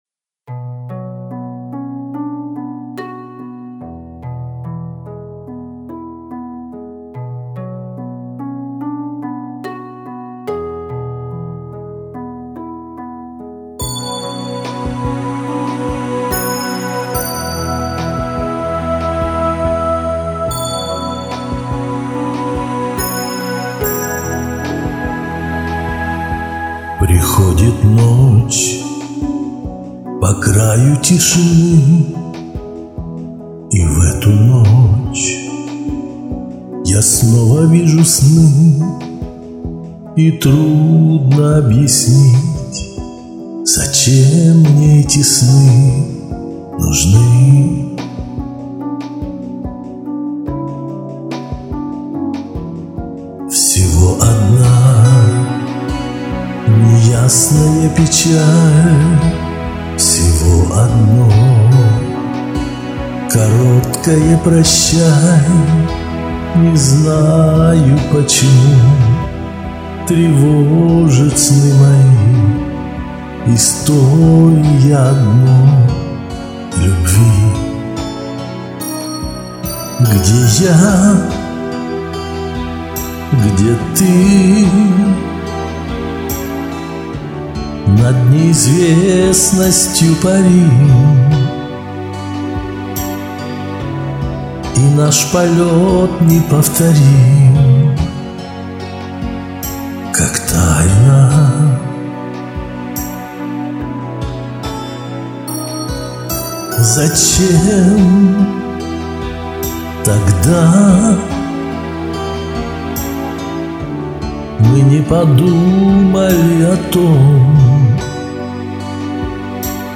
Два варианта исполнения - мужское и женское.